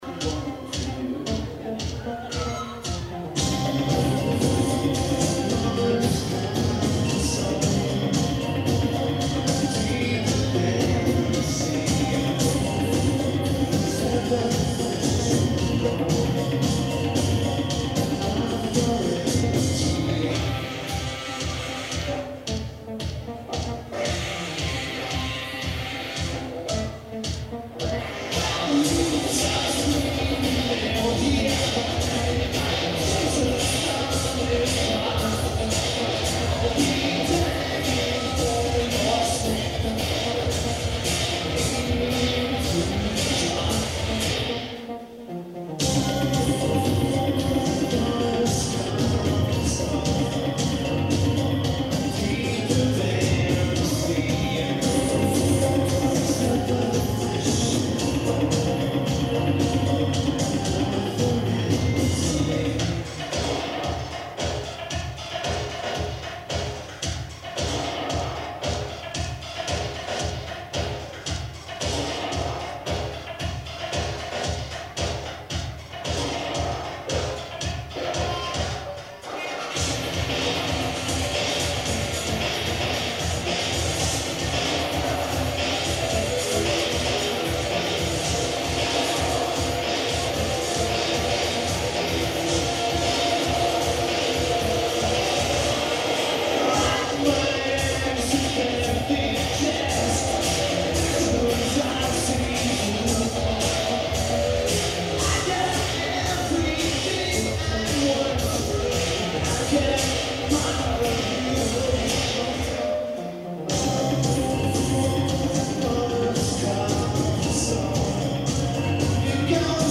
The Ritz
Lineage: Audio - AUD (Sony WM-D6 + Unknown Mic)
This is from the master tape and sounds wonderful.